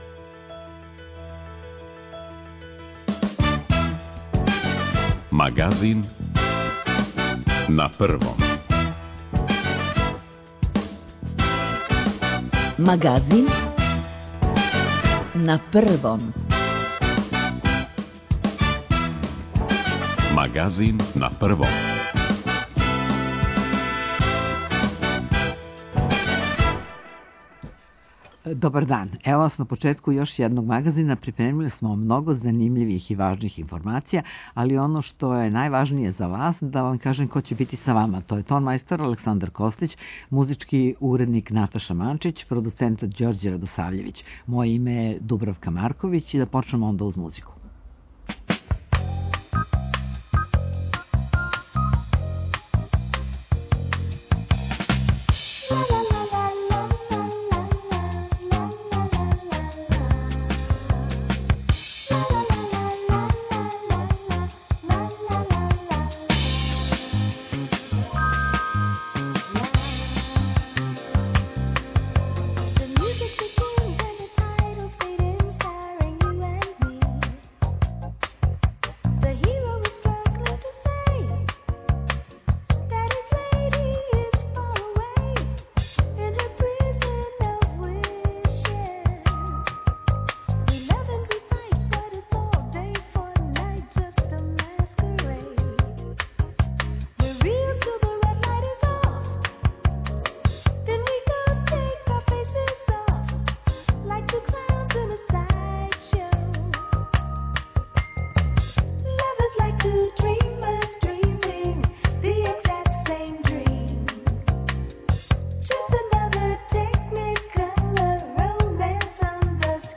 Доносимо најновије информације о епидемији која је захватила велики део света, тражимо савете стручњака о томе како се понашати у условима епидемије и ванредног стања, пратимо стање на терену, слушамо извештаје наших репортера из земље и света.
Како ће изгледати живот у главном граду после укидања ванредног стања питаћемо заменика градоначелника Београда, Горана Весића.